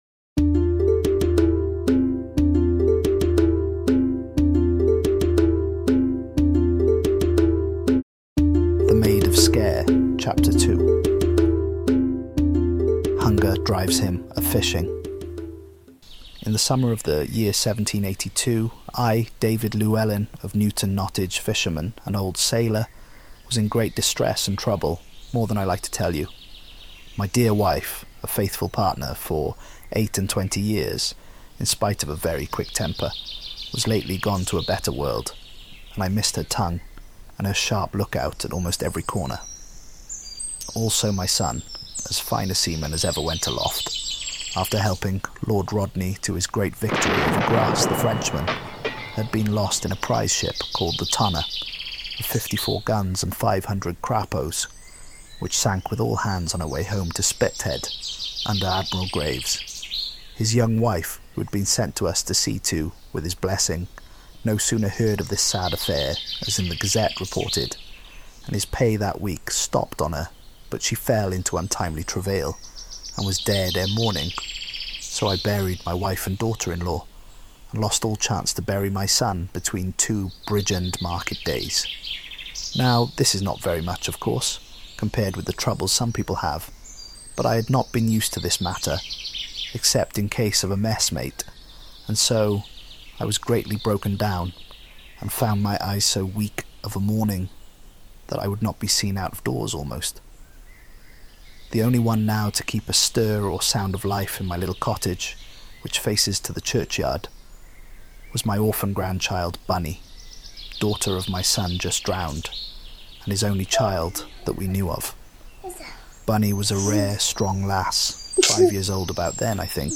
In this second episode of our unabridged reading of R.D. Blackmore’s The Maid of Sker, our narrator’s empty stomach pushes him to take up his fishing gear.